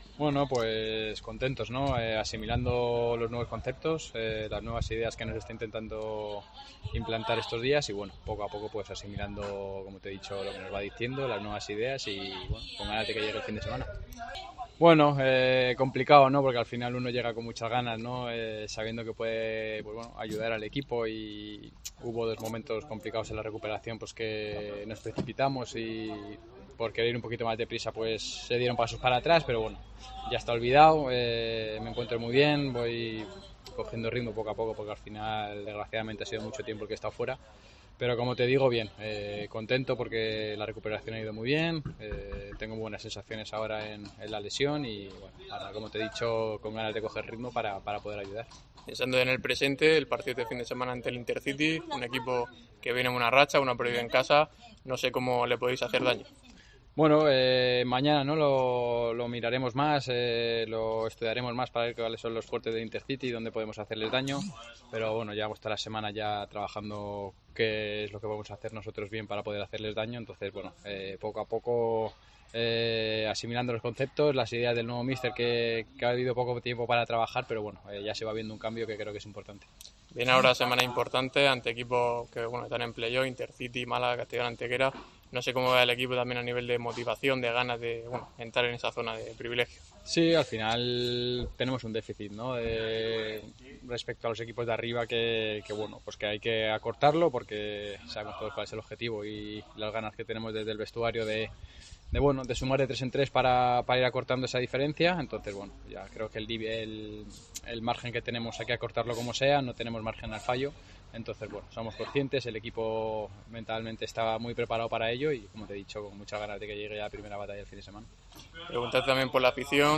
atendió a los medios de comunicación del club en rueda de prensa tras la sesión de entrenamiento de hoy en La Torre Golf Resort.